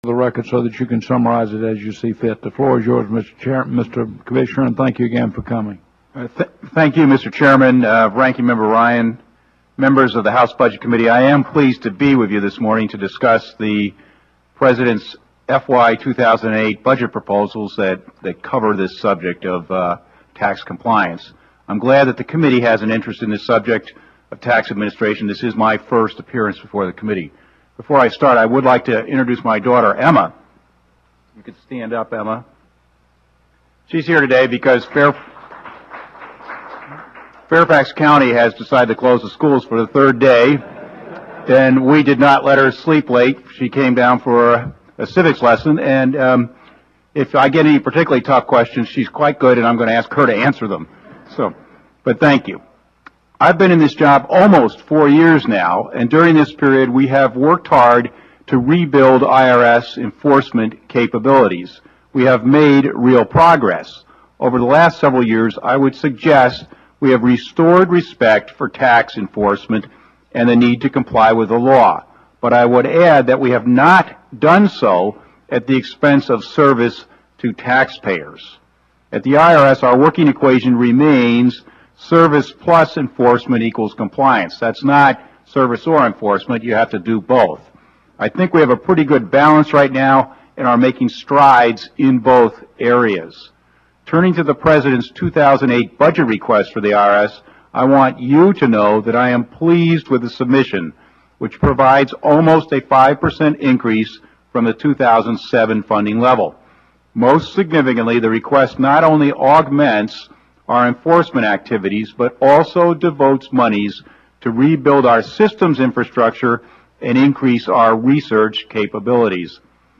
Mark Everson, IRS Commissioner:
Everson_Opening.mp3